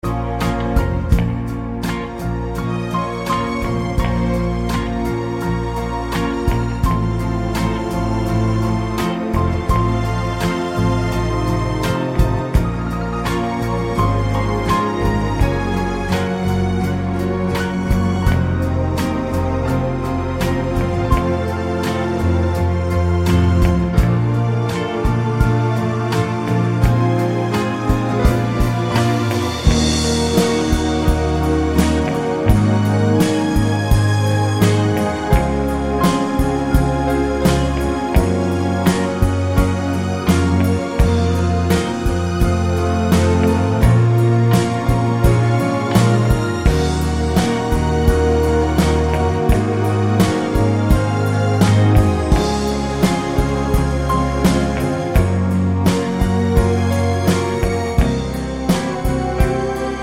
no Backing Vocals Crooners 3:10 Buy £1.50